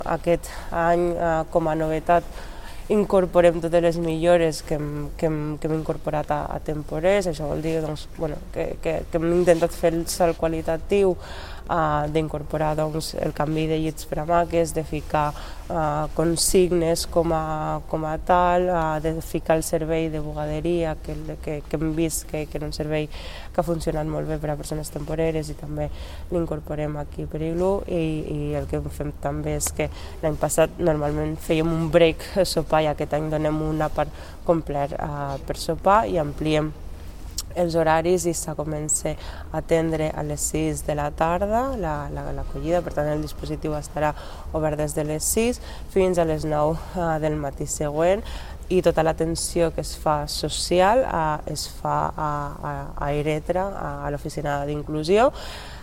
tall-de-veu-de-la-regidora-mariama-sall-sobre-el-pla-iglu-hivern-21-22